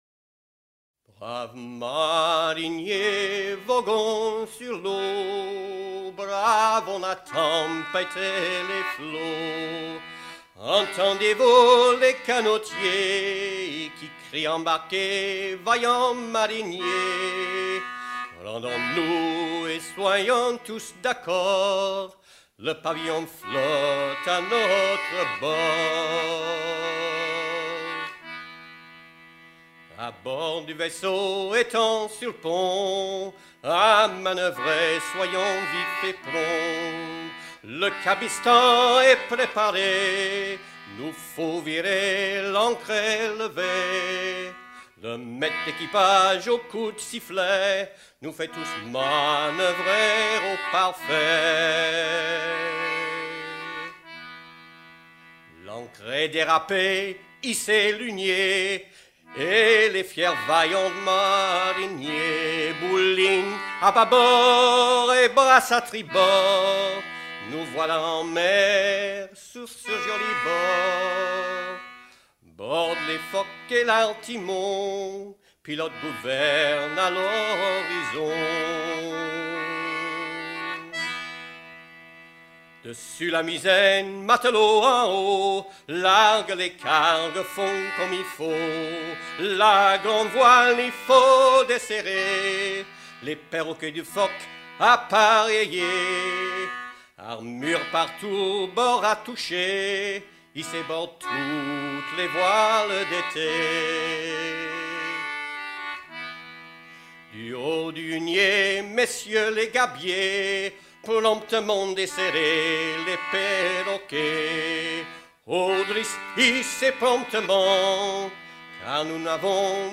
Mélodie d'un chant traditionnel recueilli dans la même commune
Pièce musicale éditée